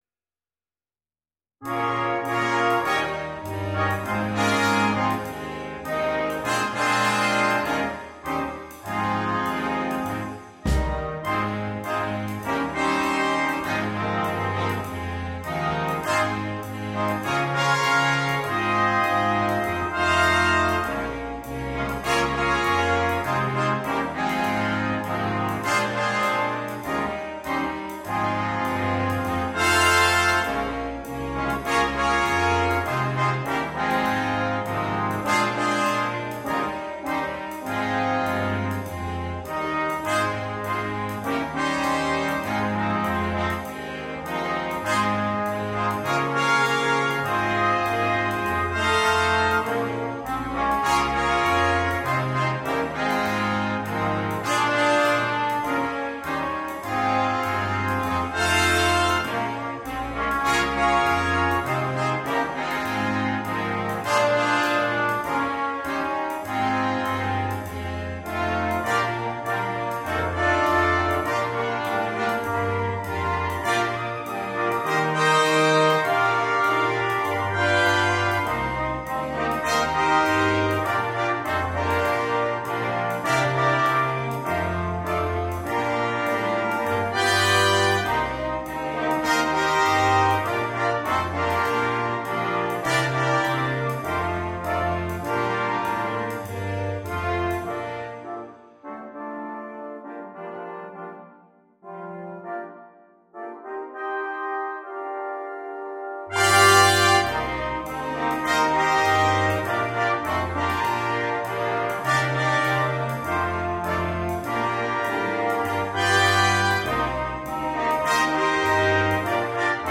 на смешанный состав.